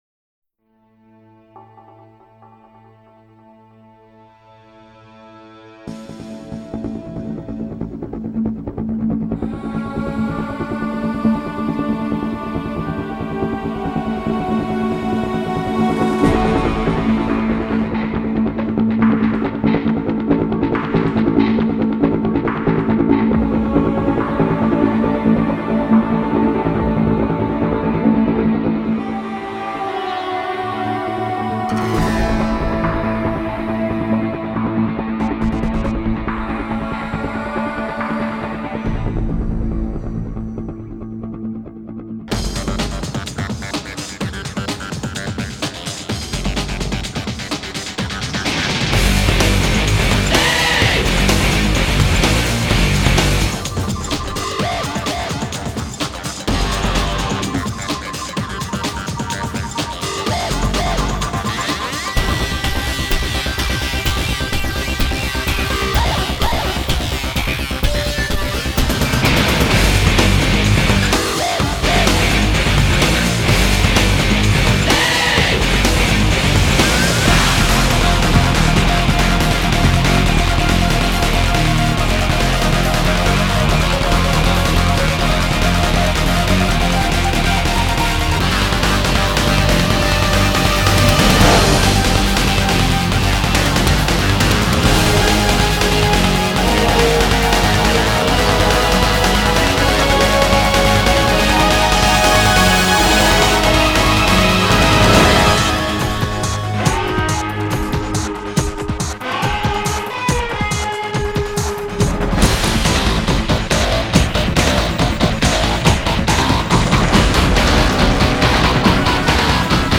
je to viac-menej elektronika s gitarami